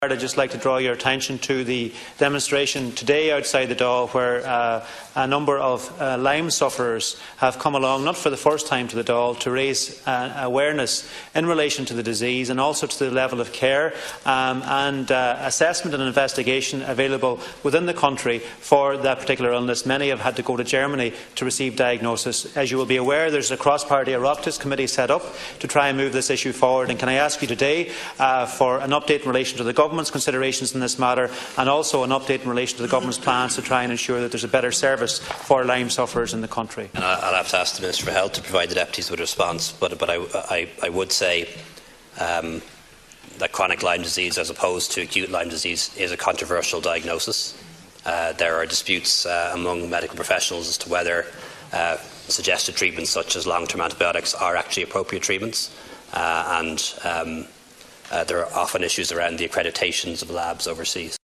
Speaking in the Dail yesterday, Deputy McConalogue told the Taoiseach a better service for people who suffer with Lyme Disease is provided: